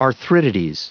Prononciation du mot arthritides en anglais (fichier audio)